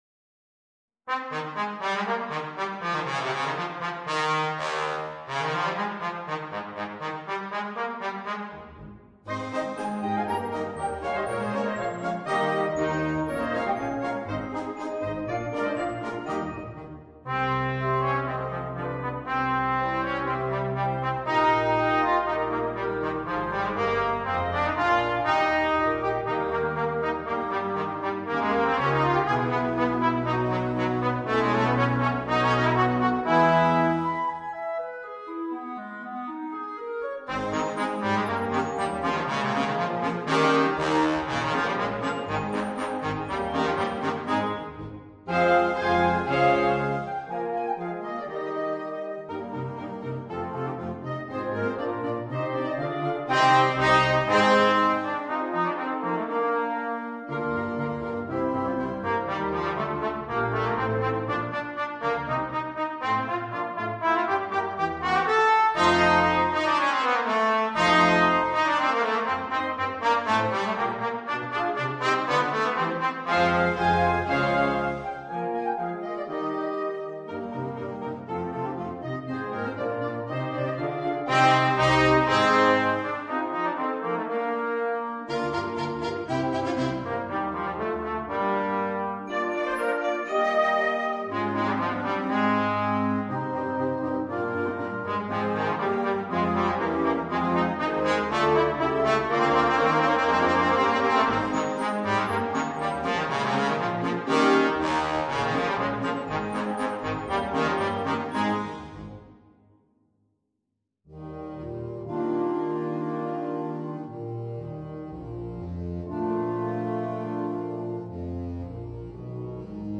Per trombone (fagotto o eufonio) e banda
Un brano per trombone solista (eufonio o fagotto) e banda.